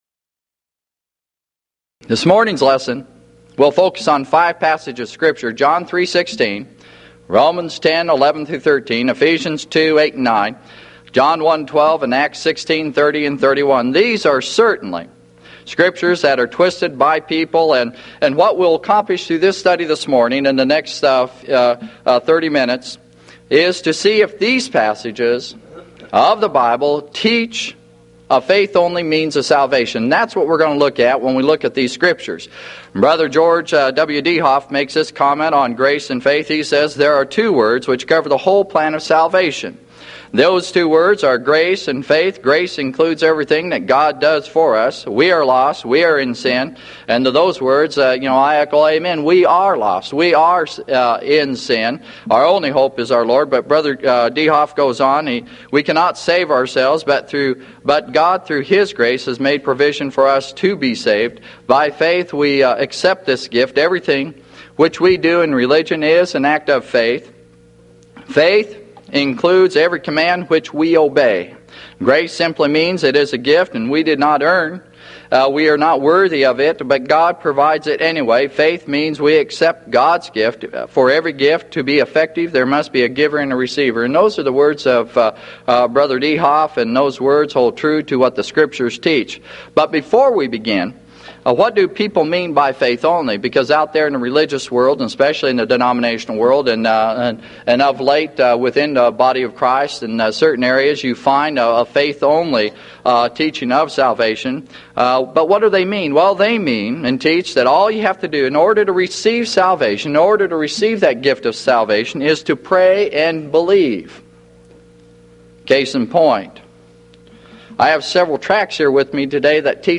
Event: 1995 Mid-West Lectures
lecture